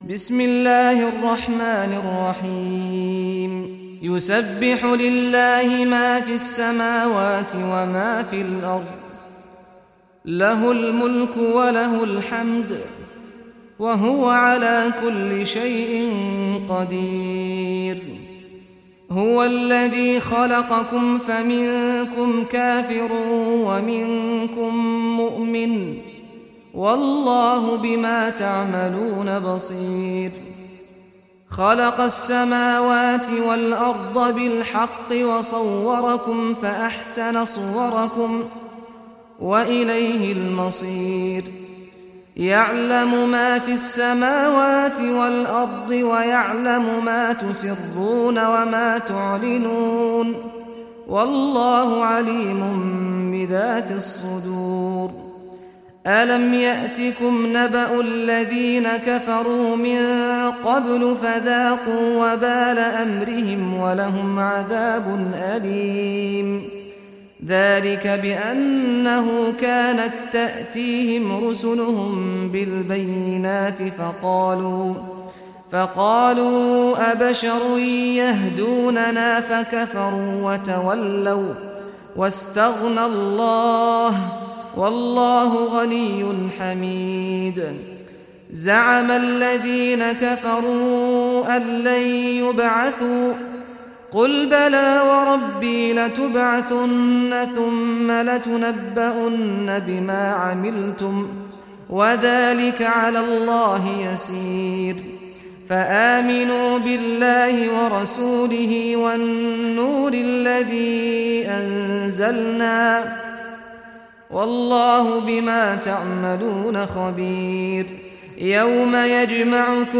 Surah At-Taghabun Recitation by Saad Al Ghamdi
Surah At-Taghabun, listen or play online mp3 tilawat / recitation in Arabic in the beautiful voice of Sheikh Saad al Ghamdi.